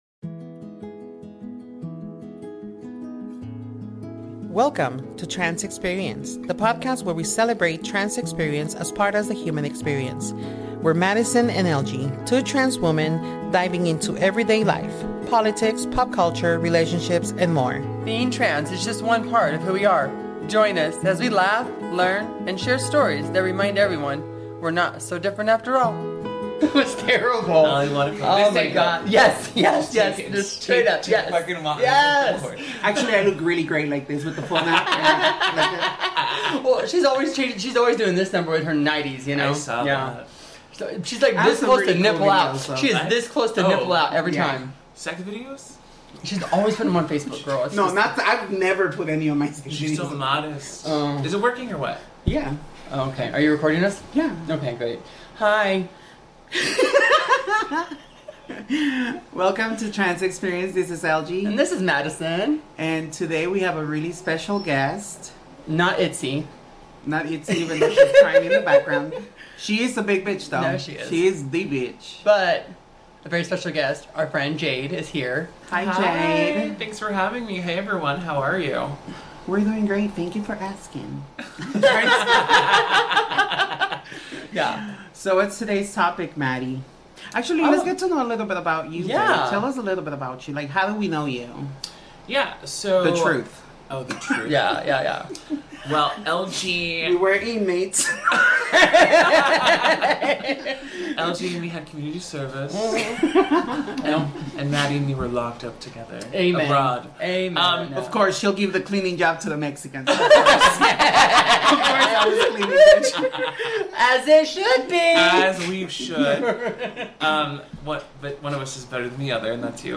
4 trans women sharing their own experiences of life, love, recovery and the pursuit of happiness.